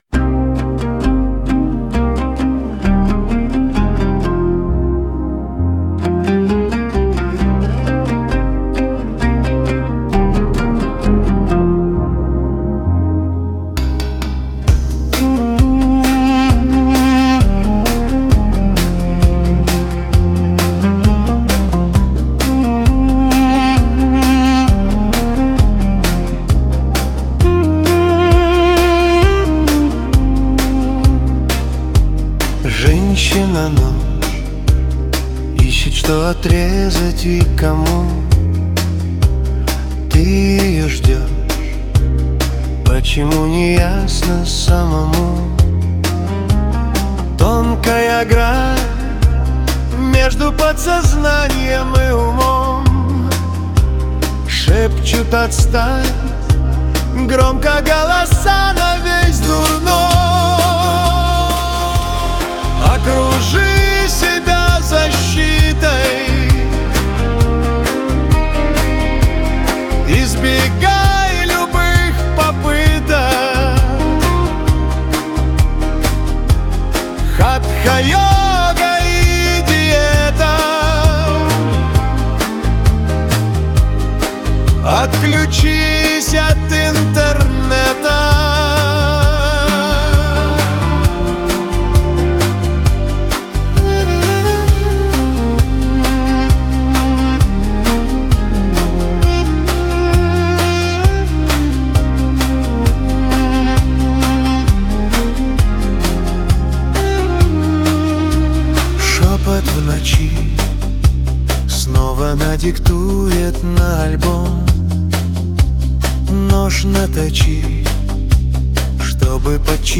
Один - мультиинструменталист, другой - бас и ударные.
Фолк